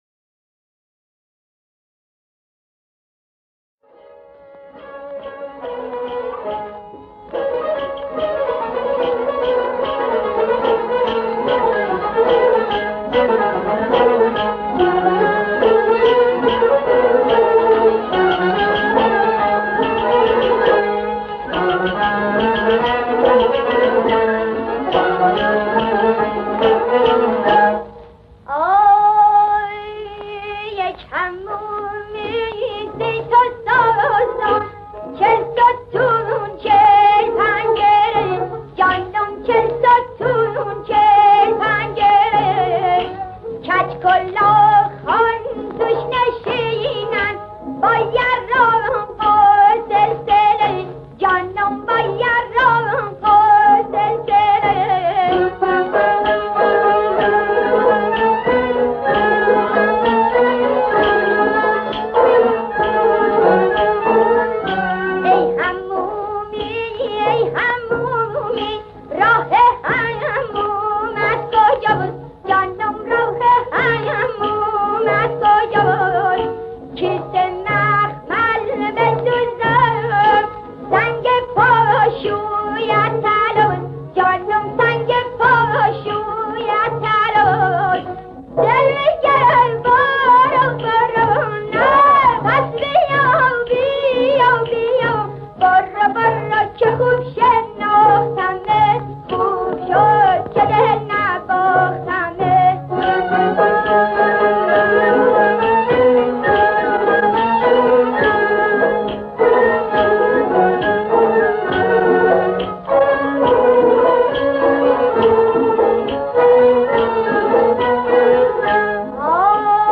ترانه سرا: (فلکلور)
ترانه ساز: (فلکلور)